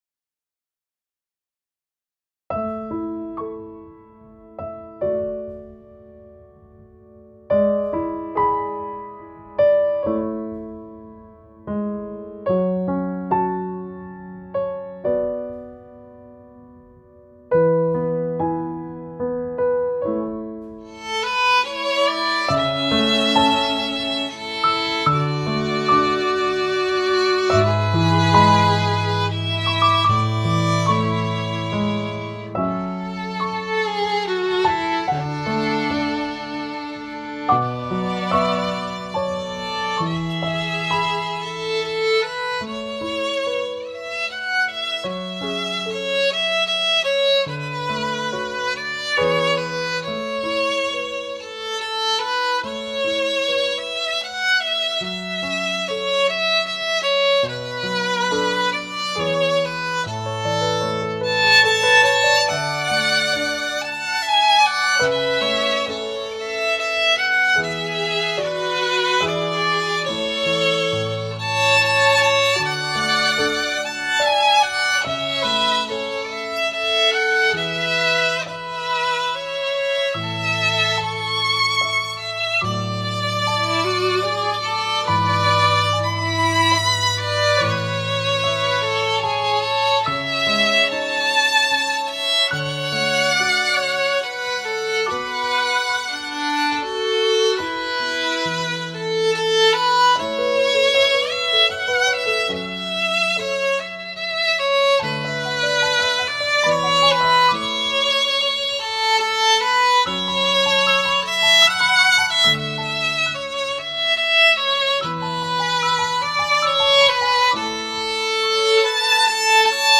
Instrumentation: Violin Duet with Piano